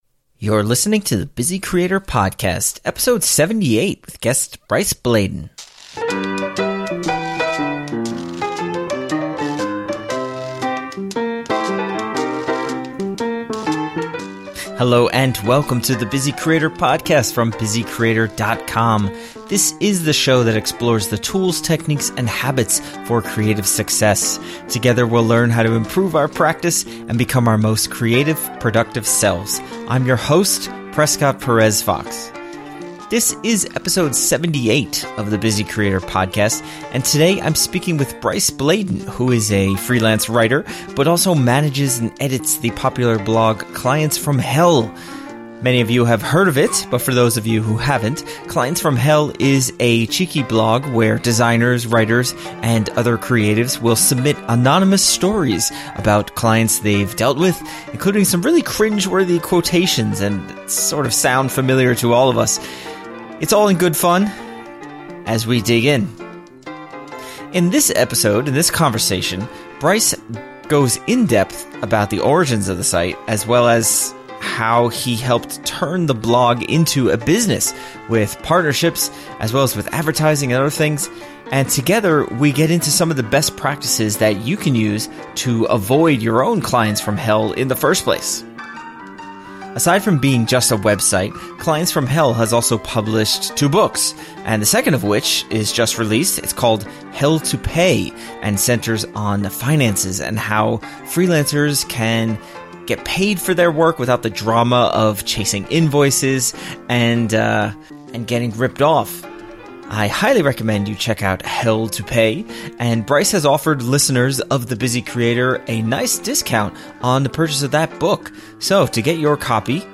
In our conversation, we discuss the origins of Clients From Hell, how a blog can become a viable business, and how all of us who provide services can avoid clients from hell to have a better working life.